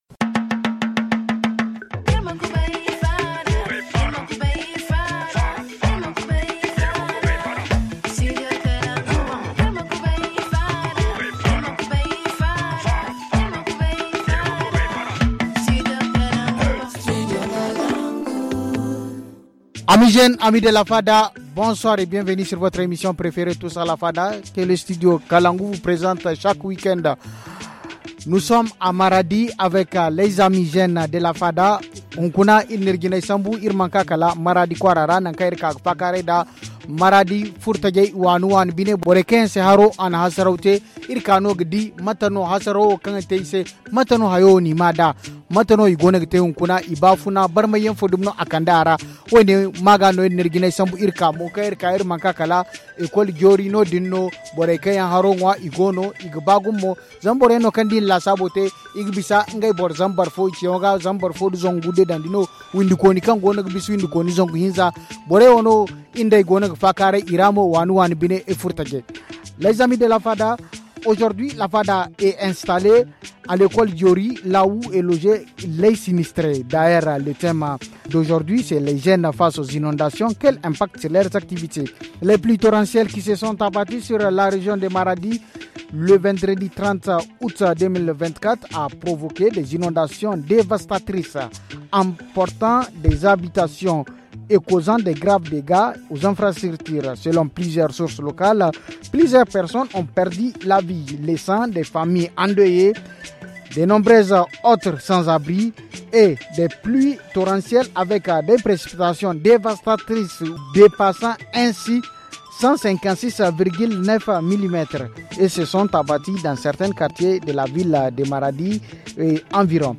À l’école Diori, où nous sommes installés, 316 ménages ont été accueillis pour une durée de 50 jours.